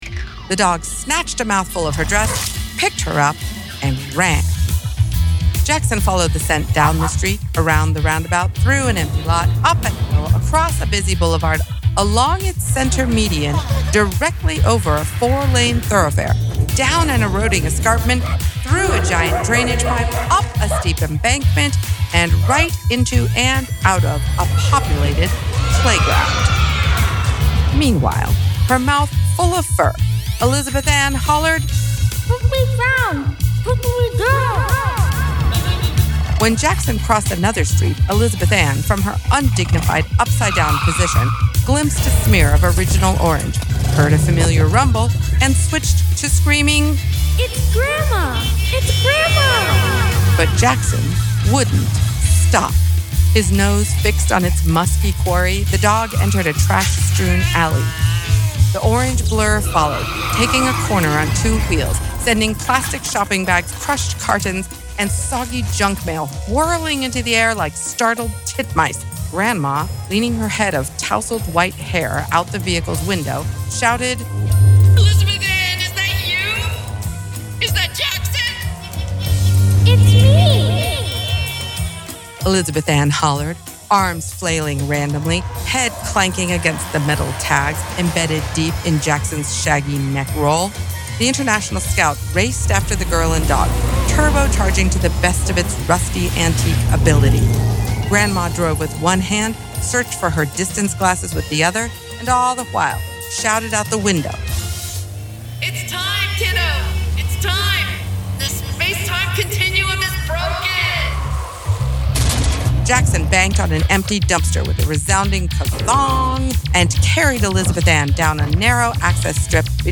Bits Of String Too Small To Save by Ruby Peru (AUDIOBOOK EXCERPT) – The Frumious Consortium
And here is the charming audio sample, from a different part of the book!